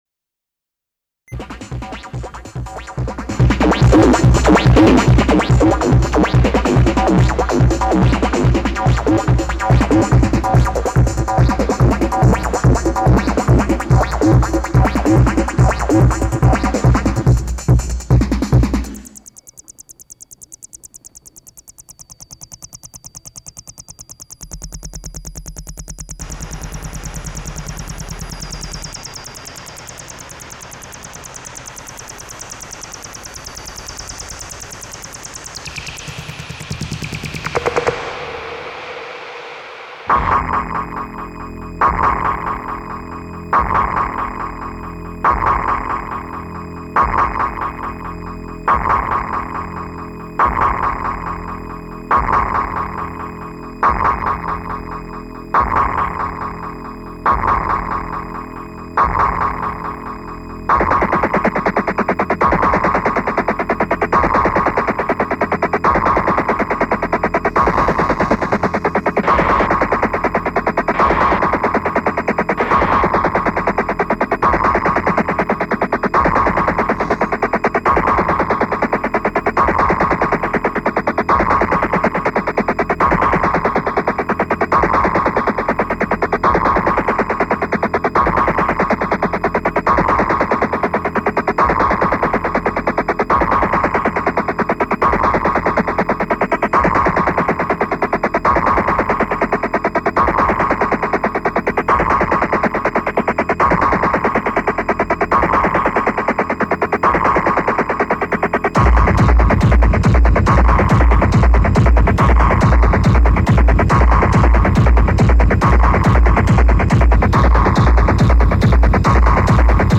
location France, Paris venue Paris le Bourget